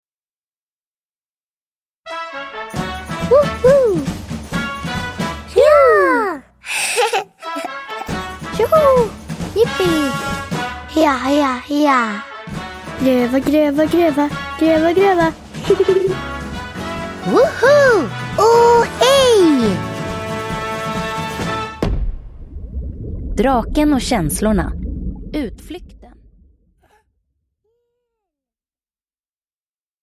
Utflykten (ljudbok) av Arvid Tappert